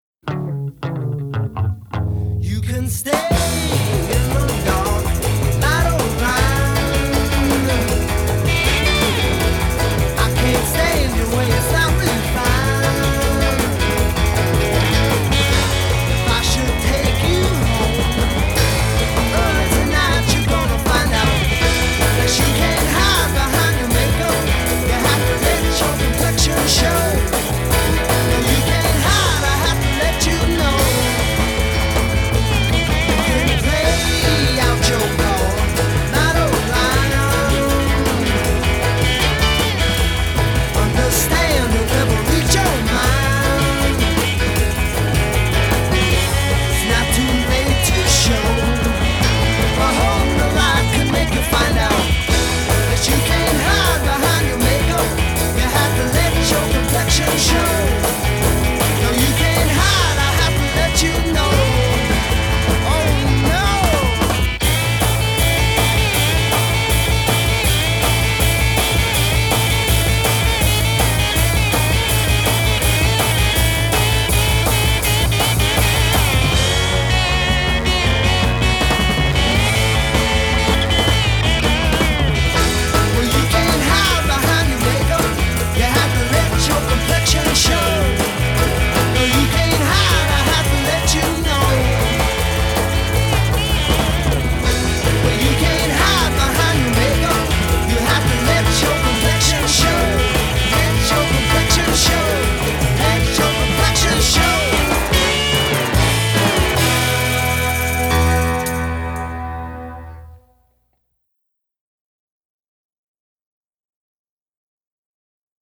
sounded vaguely rock and roll in a conventional sense